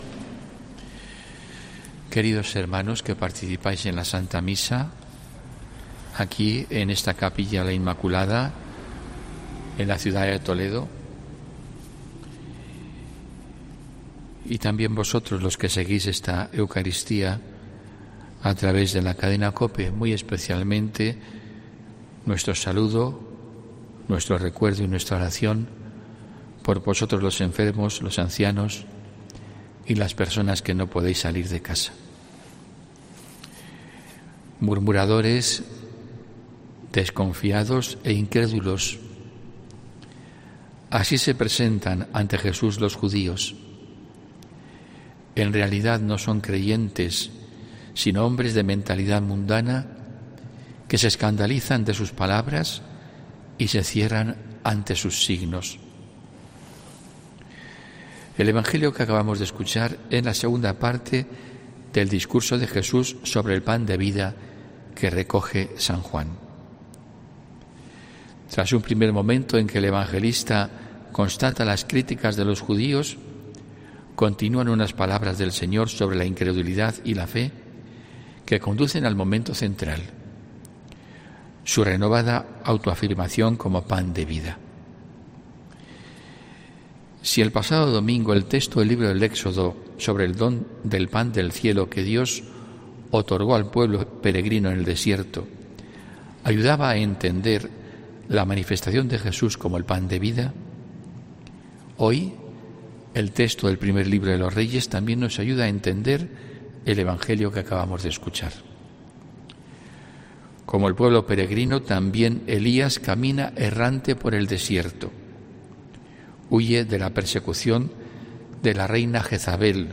HOMILÍA 8 AGOSTO 2021